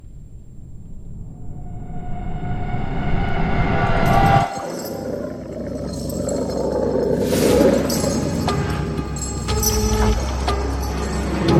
موسیقی کنار تو